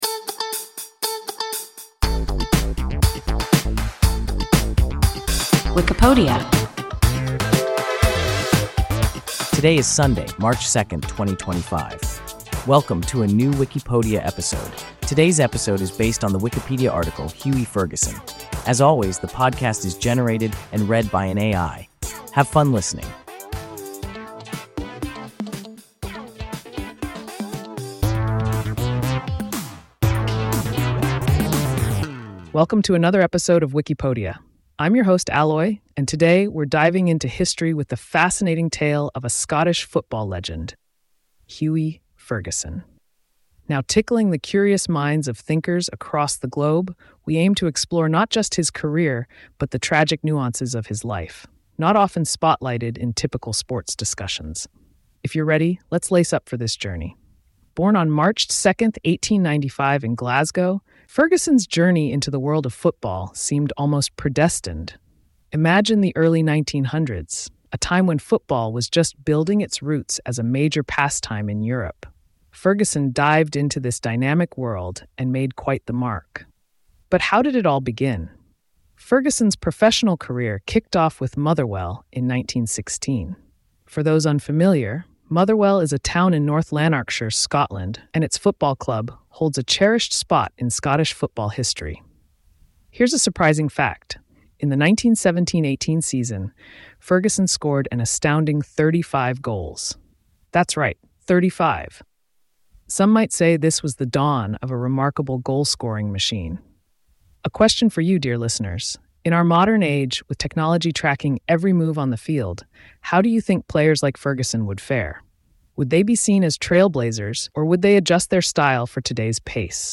Hughie Ferguson – WIKIPODIA – ein KI Podcast